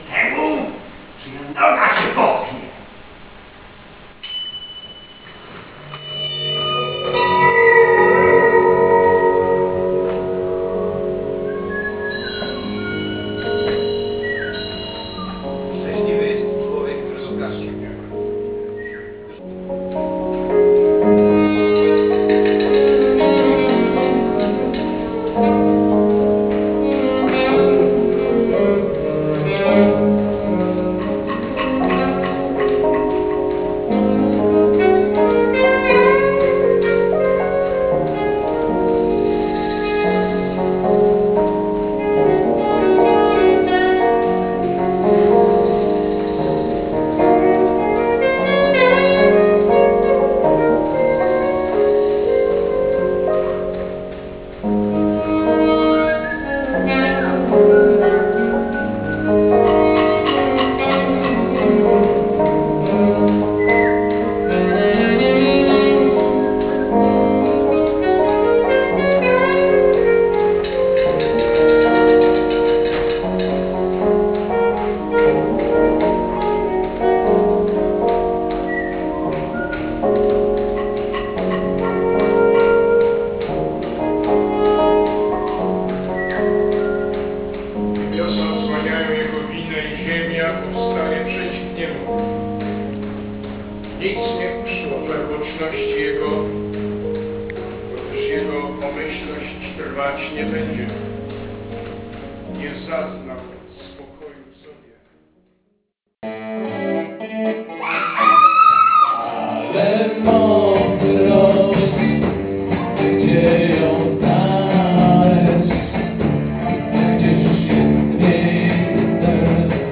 Współczesny (Contemporary) Theatre